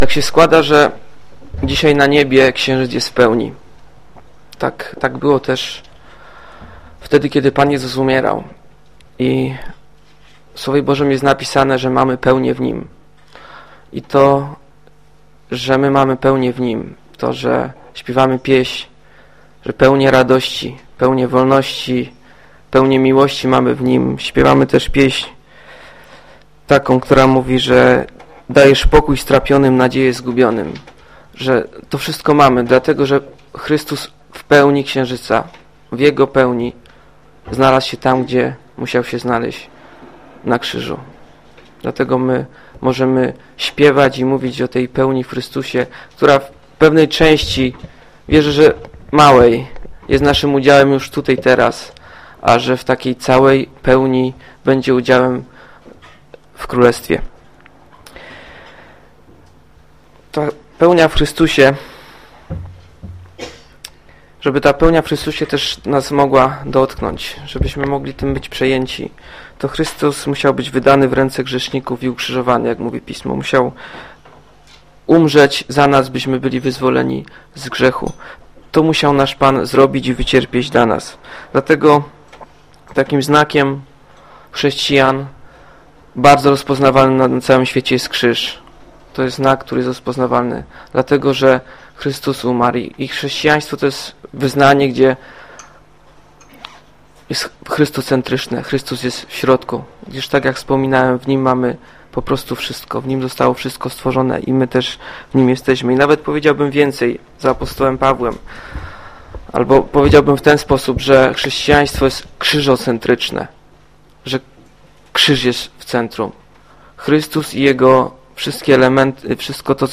Kazania Wieczerza Pańska, Pamiątka Pana Jezusa, nauczanie mp3